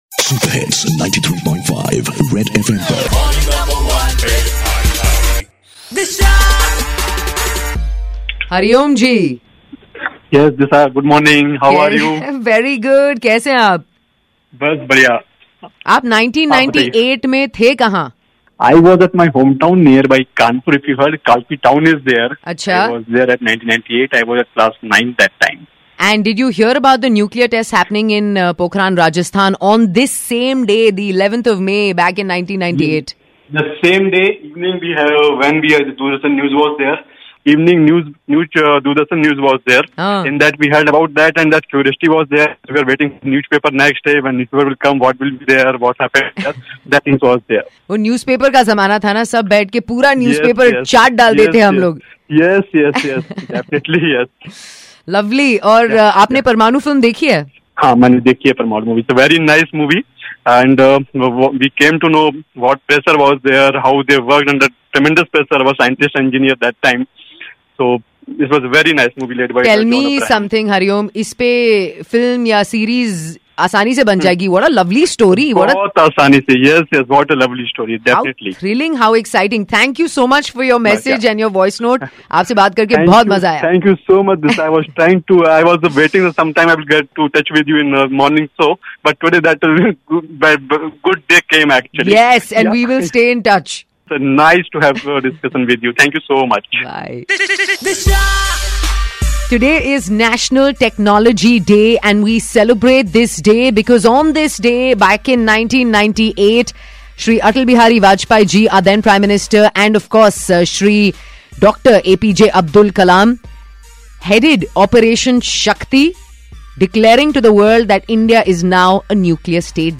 in conversation with listener